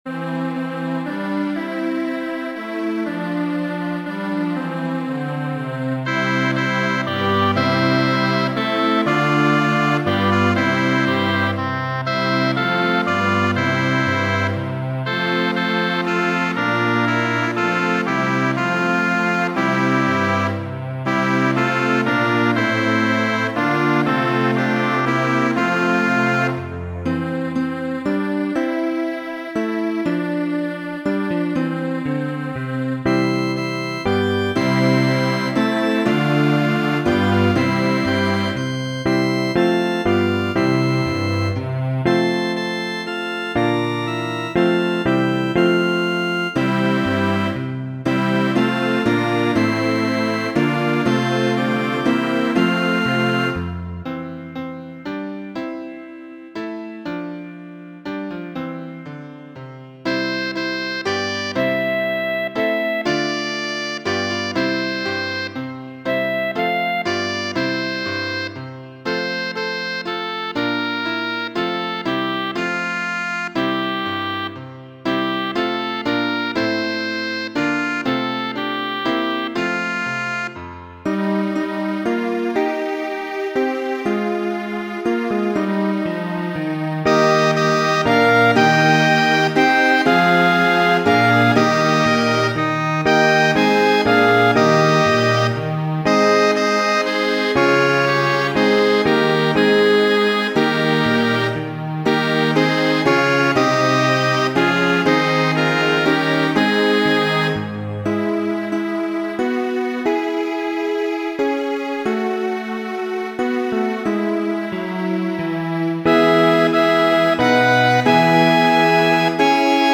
Muziko :
Honoro al dio, popolkanto ĉeĥa midigita de mi mem.